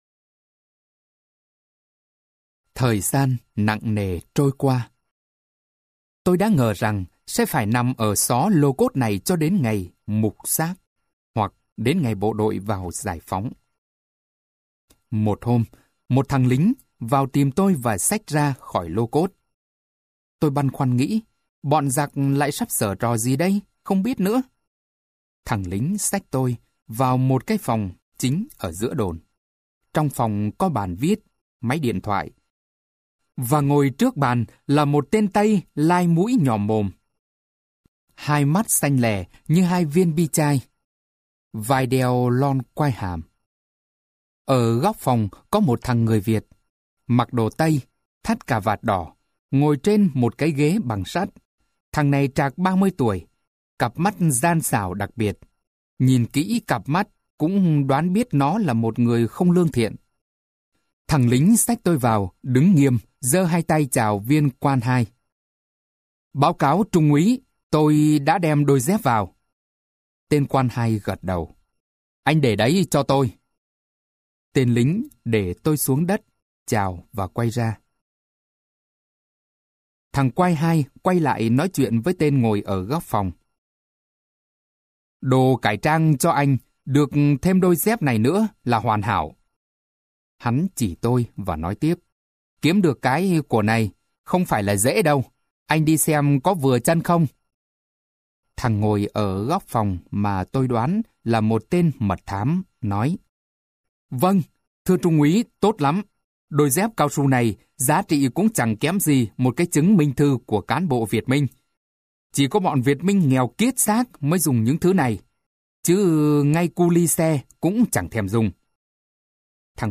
Sách nói Cuộc Đời Một Đôi Dép Cao Su - Phùng Quán - Sách Nói Online Hay